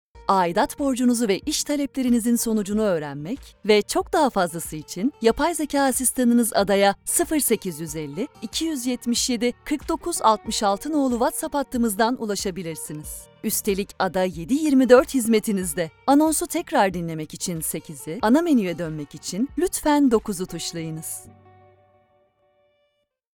Profundo, Natural, Cool, Cálida, Empresarial
Telefonía
She has her own professional home studio.